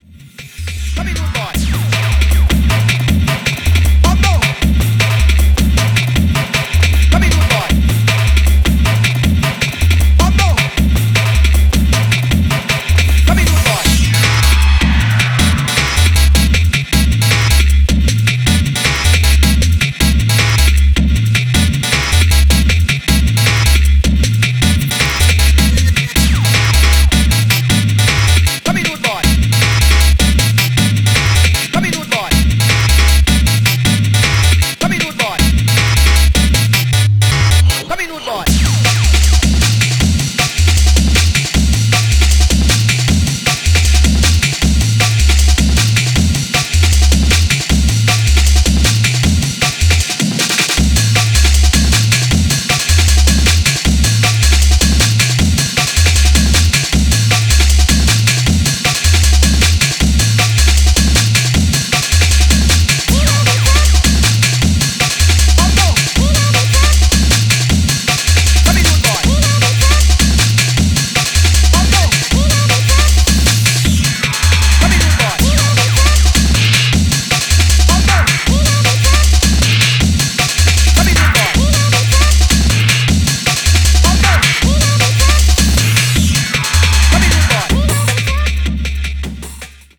GENRE(S): JUNGLE